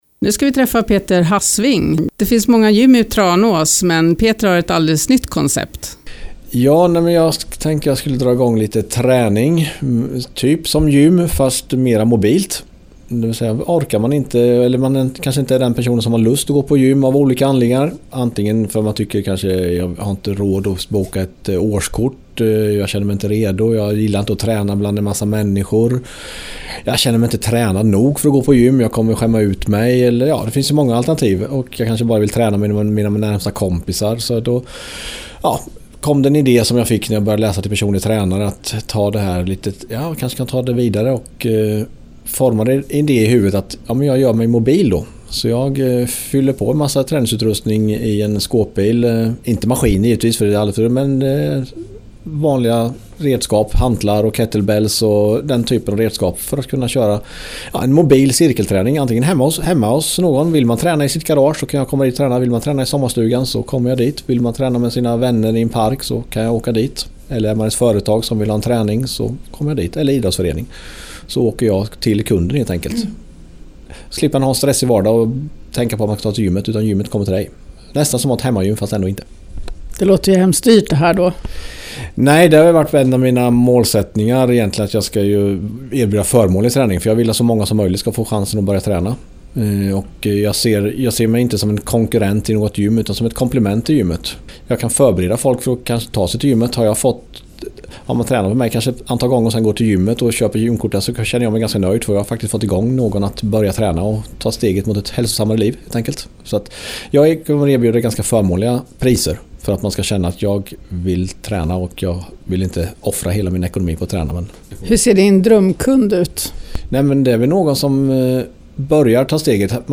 Del av radioprogrammet